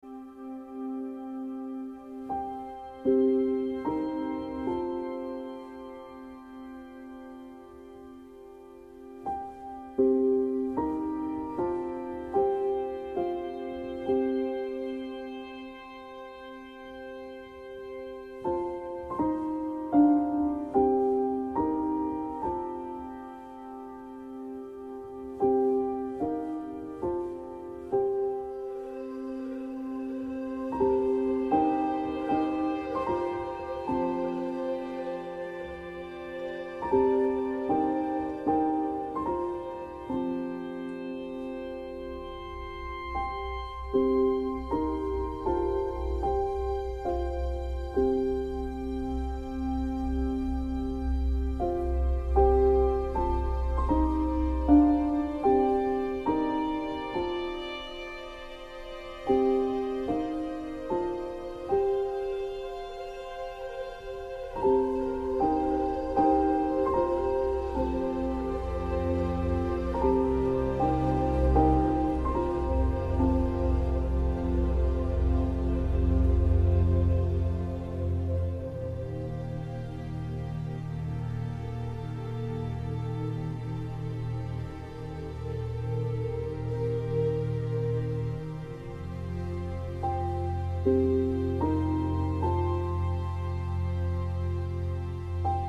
exquisitas partituras minimalistas